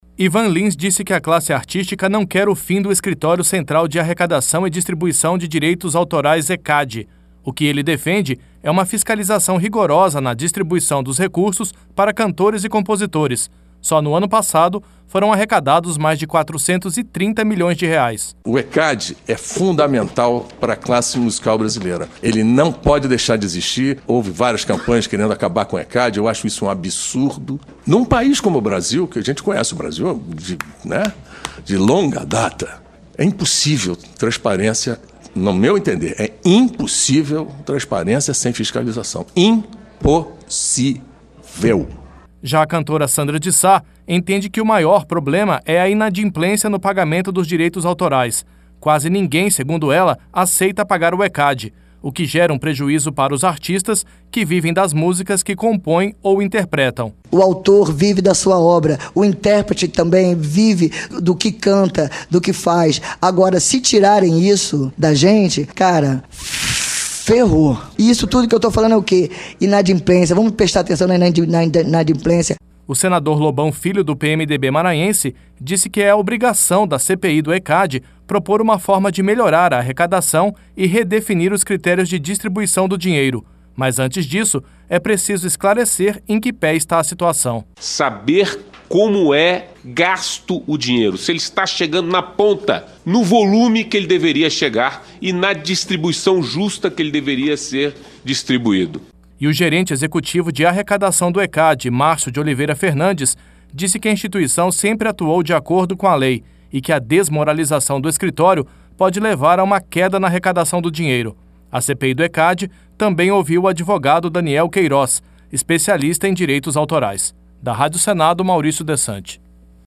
ARTISTAS DEFENDEM FISCALIZAÇÃO DO ECAD E FIM DA INADIMPLÊNCIA NO PAGAMENTO DE DIREITOS AUTORAIS NO BRASIL. IVAN LINS E SANDRA DE SÁ PARTICIPARAM NA TARDE DESTA QUARTA-FEIRA DA REUNIÃO DA CPI DO ECAD.